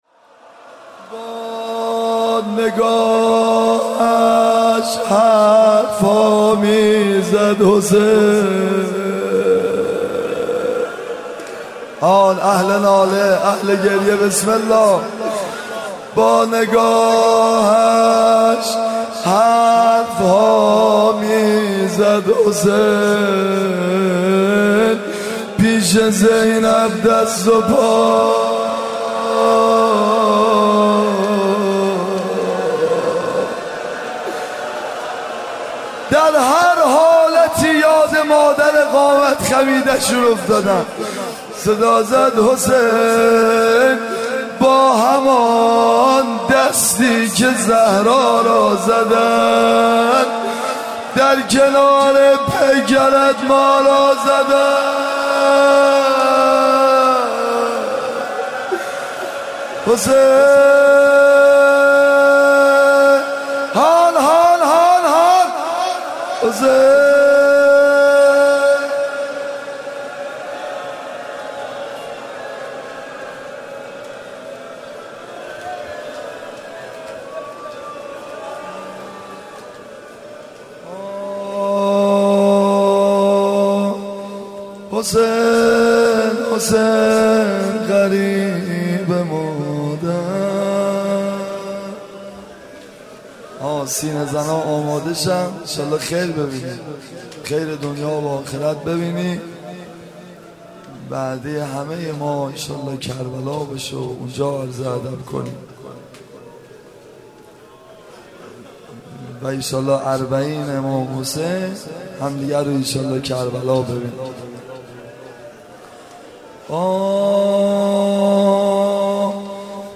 عقیق:مراسم عصر عاشورا محرم95/هیئت میثاق با شهدا